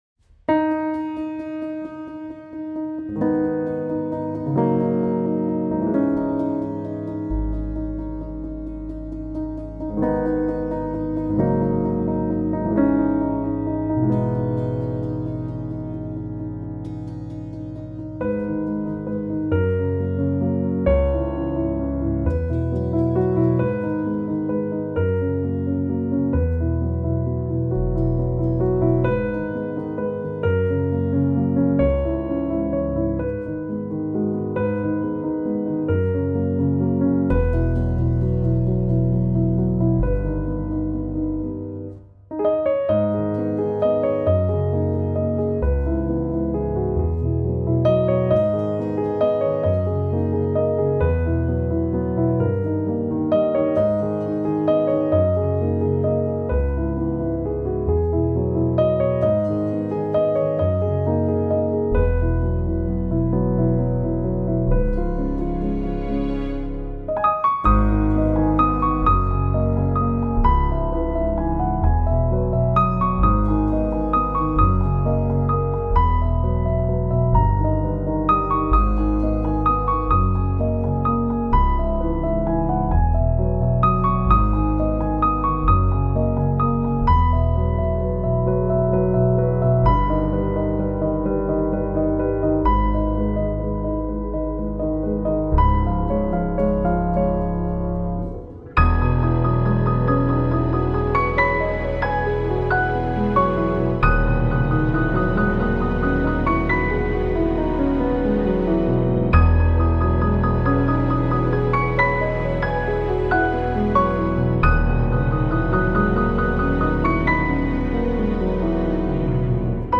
سبک آرامش بخش , پیانو , موسیقی بی کلام